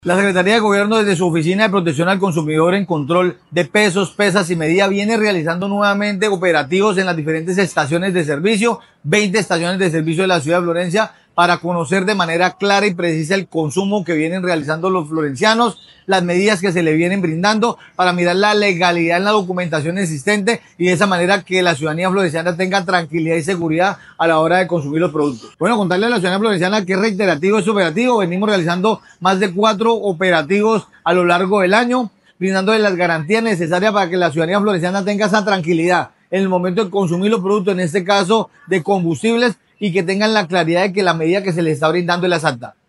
El secretario de gobierno municipal, Carlos Humberto Mora Trujillo, dijo que estas acciones, realizadas a través de la oficina de protección al consumidor en control de precios, pesas y medidas, tuvieron como propósito verificar la documentación legal vigente y garantizar la medida exacta en el suministro de combustible.